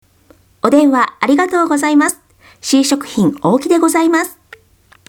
改善後音声